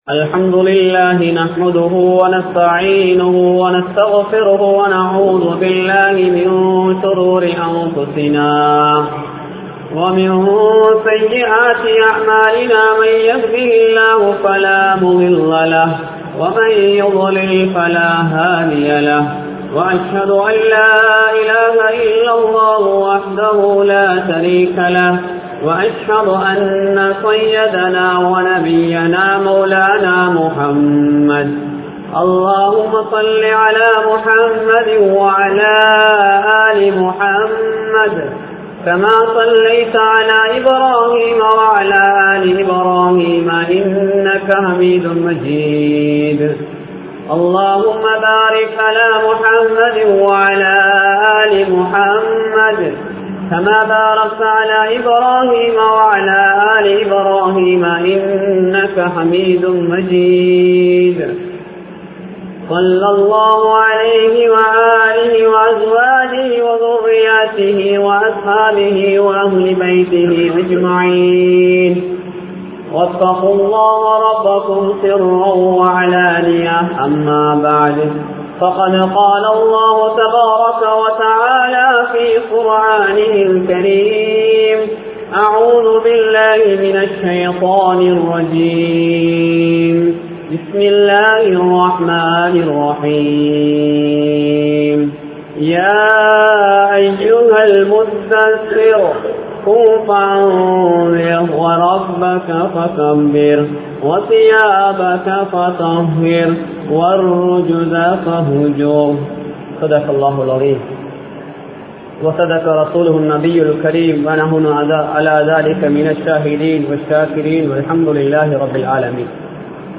Islam Valiuruththum Suththam (இஸ்லாம் வலியுறுத்தும் சுத்தம்) | Audio Bayans | All Ceylon Muslim Youth Community | Addalaichenai